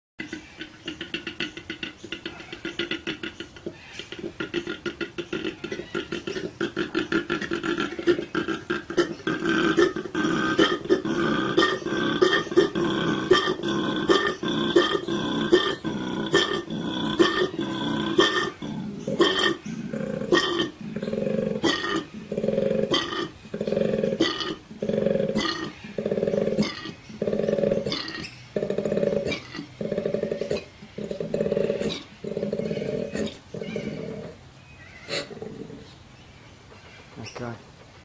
Звуки коалы
Такой звук издает это животное